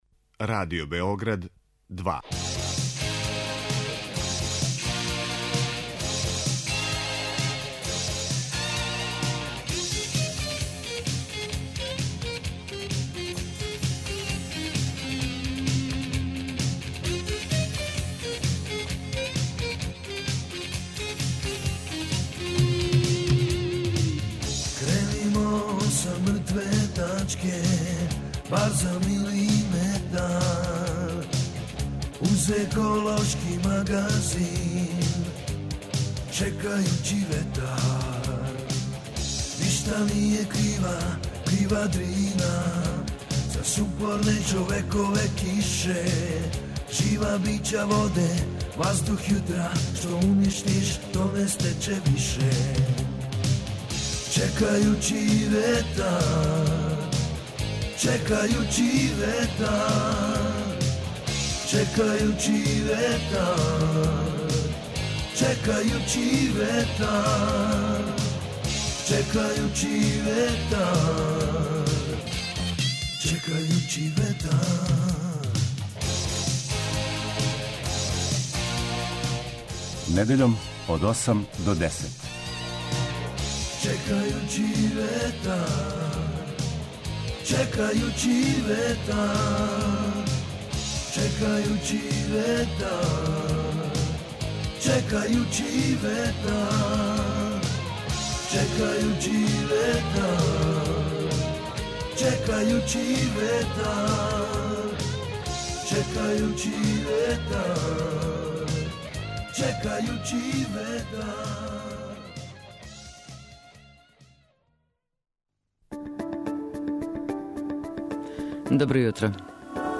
И овогодишња свечана додела признања ЗЕЛЕНИ ЛИСТ и етикете ЦРНИ ЛИСТ, одржана је у сали Гимназије у Сремским Карловцима.